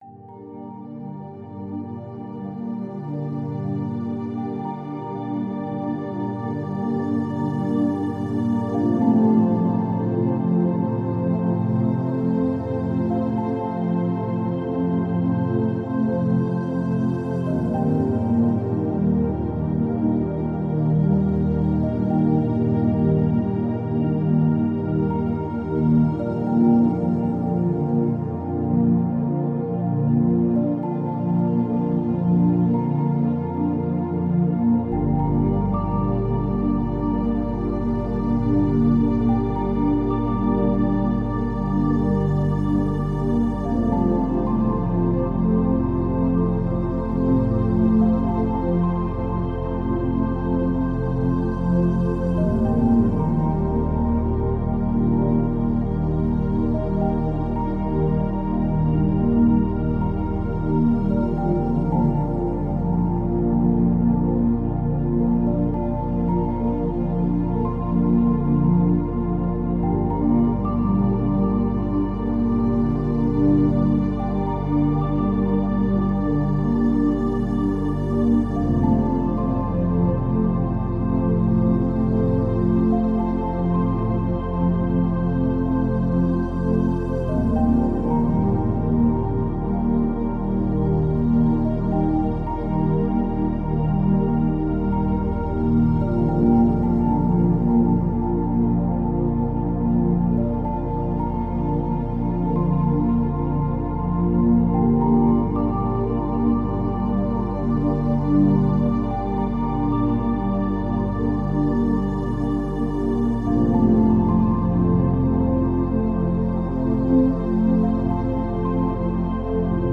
ambience ambient atmosphere background breeze calm calming chill sound effect free sound royalty free Memes